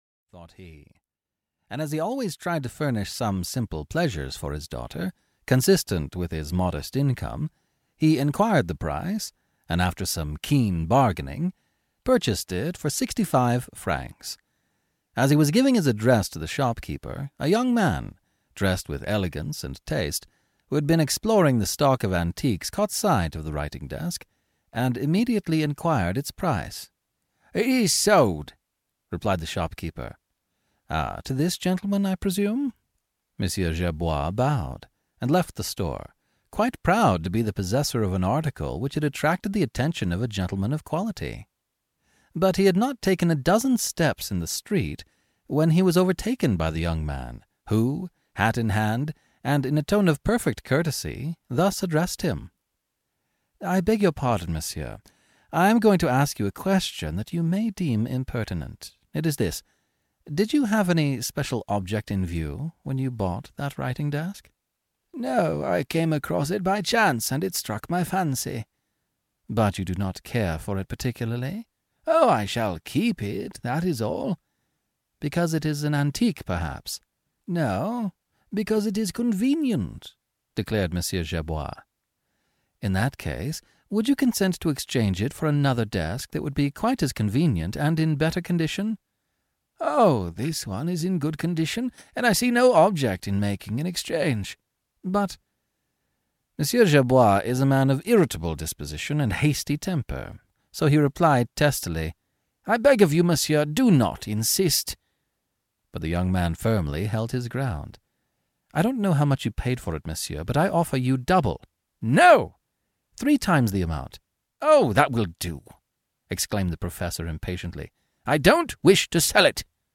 Arsene Lupin versus Herlock Sholmes (EN) audiokniha
Ukázka z knihy